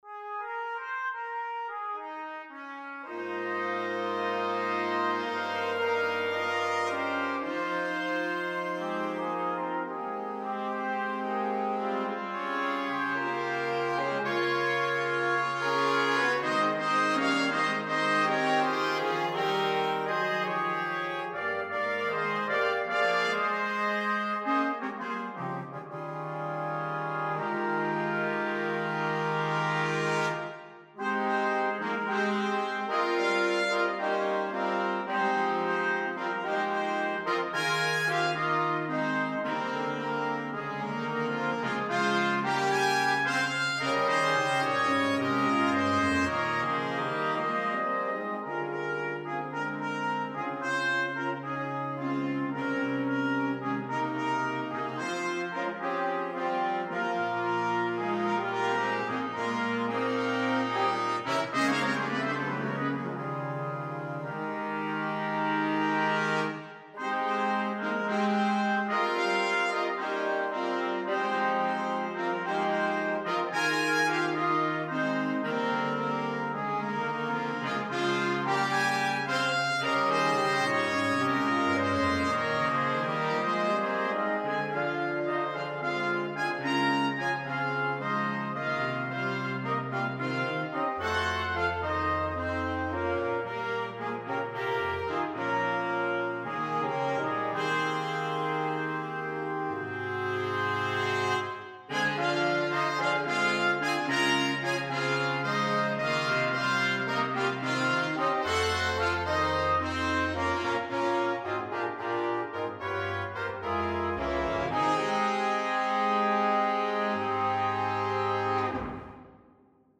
4 saxes, dois trompetes e um trombone